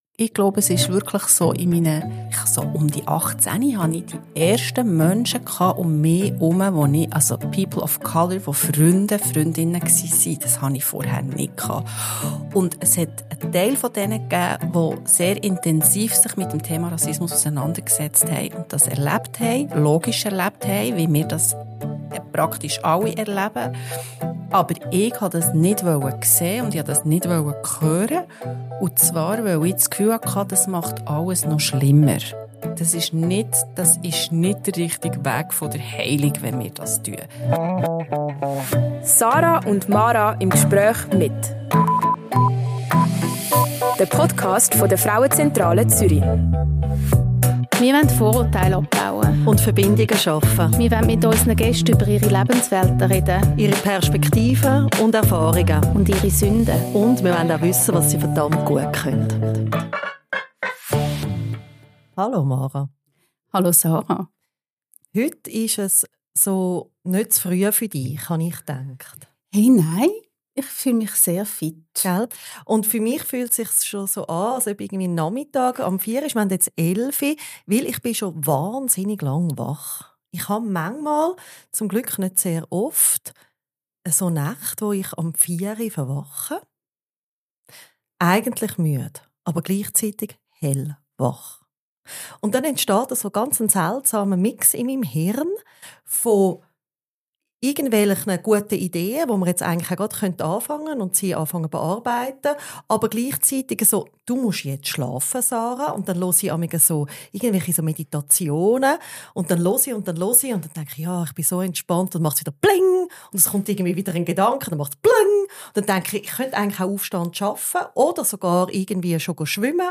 Sie sprechen mit Angélique Beldner, die als Journalistin, Moderatorin, Schauspielerin und Autorin für den SRF arbeitet, über Themen, die wach halten. Im Kopf, im Körper und in der Gesellschaft.